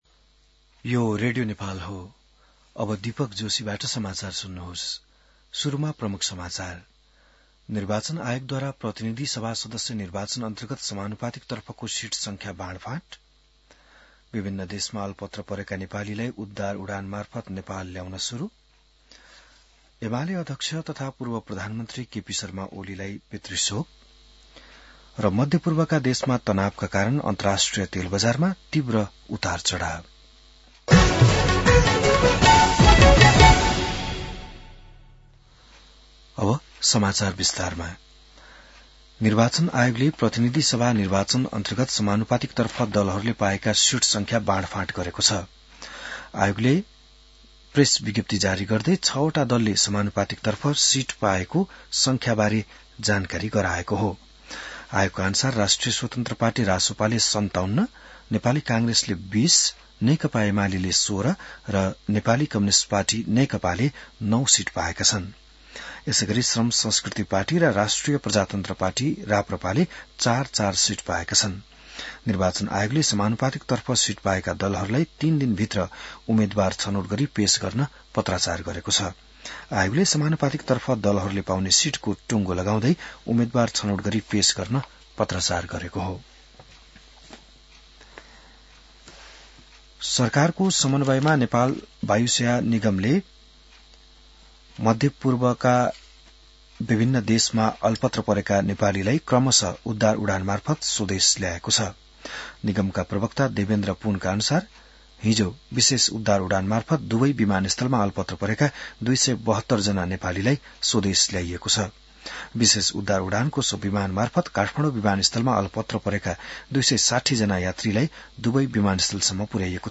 बिहान ९ बजेको नेपाली समाचार : २९ फागुन , २०८२